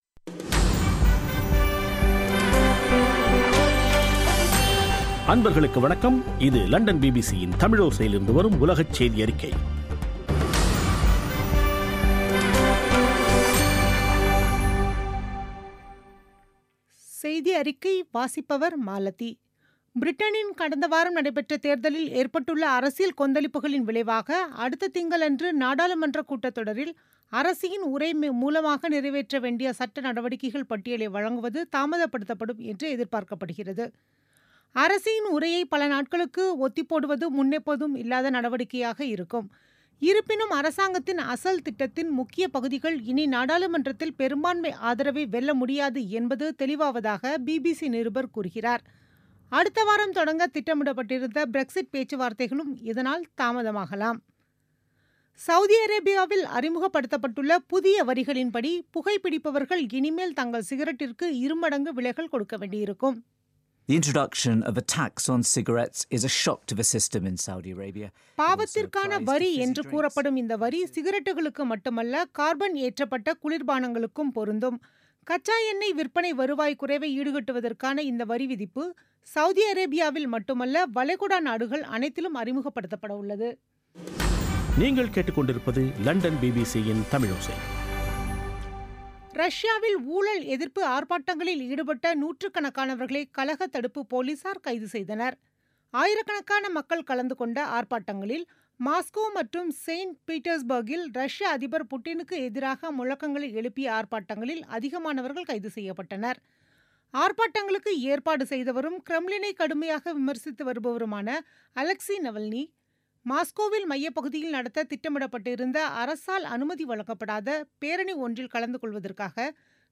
பிபிசி தமிழோசை செய்தியறிக்கை (12/06/2017)